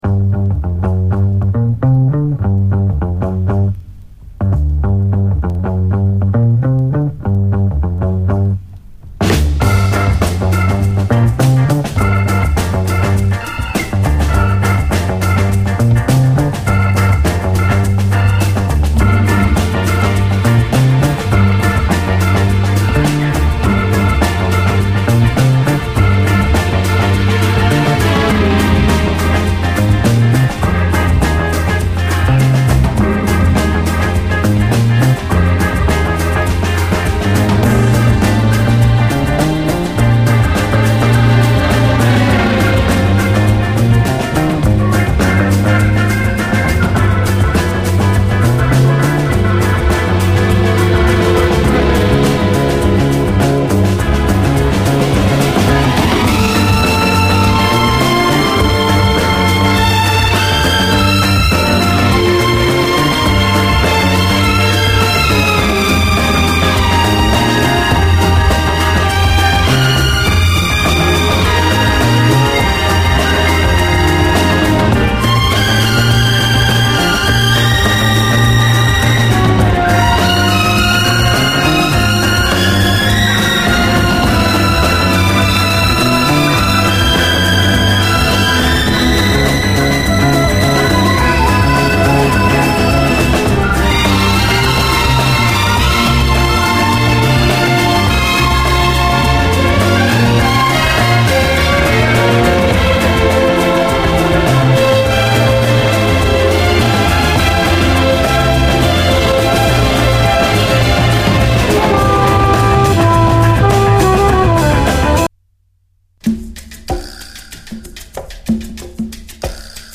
SOUL, JAZZ FUNK / SOUL JAZZ, 70's～ SOUL, JAZZ
ファンキーなグルーヴと幽玄なストリングスが一体となったオンリーワンな一曲です！